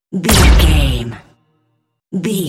Dramatic hit laser
Sound Effects
heavy
intense
dark
aggressive